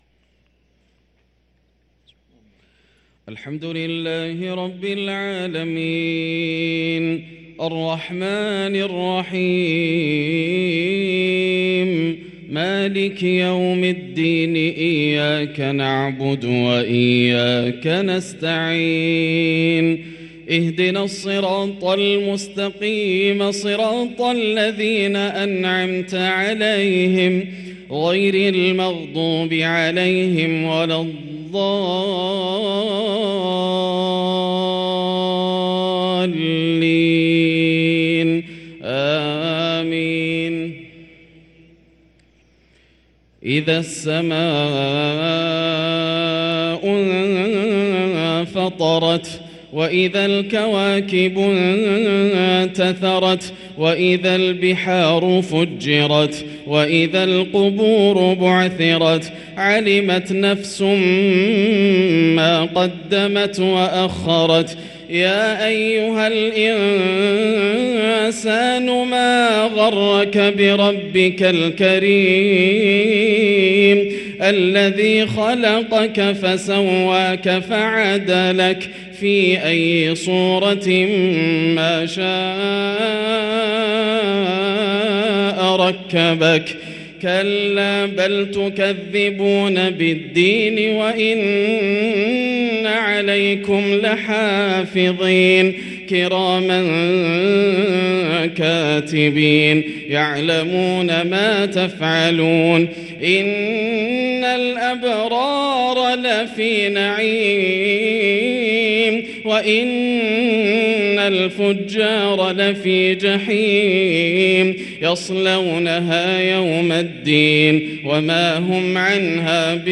صلاة المغرب للقارئ ياسر الدوسري 21 رجب 1444 هـ
تِلَاوَات الْحَرَمَيْن .